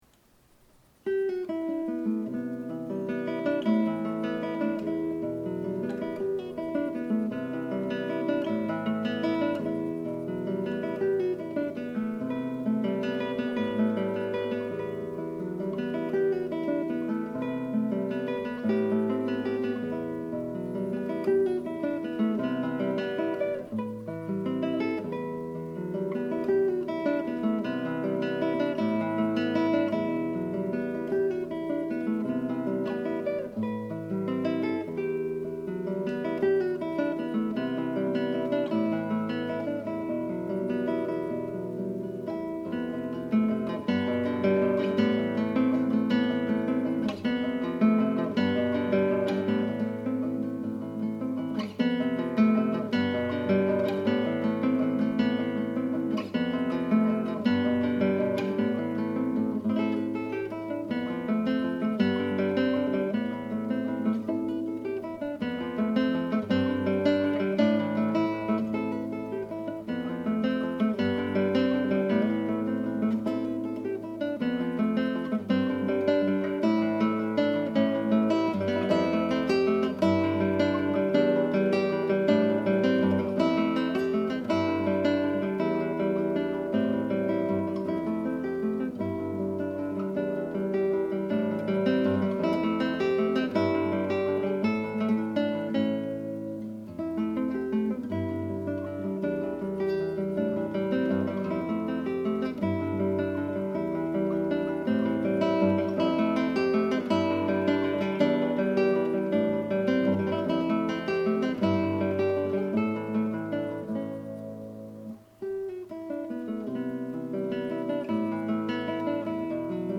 realizzato in modo casalingo